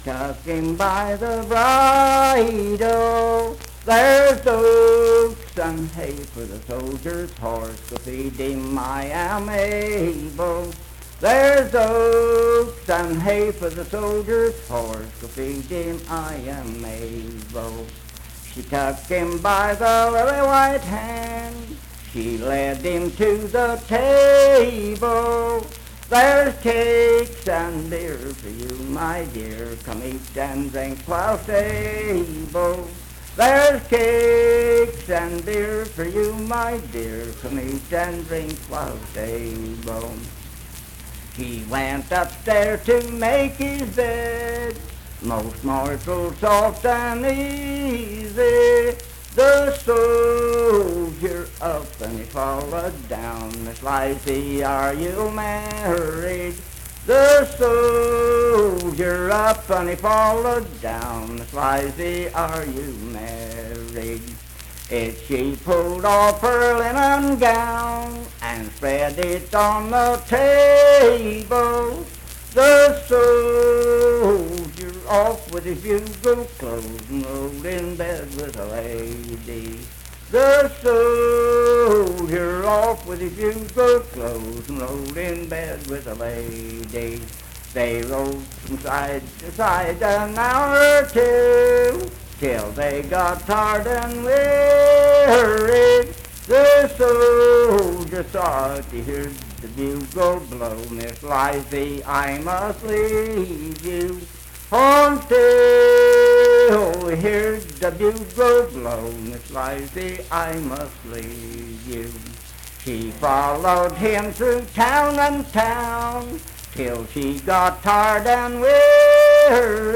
Unaccompanied vocal music
Verse-refrain 5 (6w/R).
Voice (sung)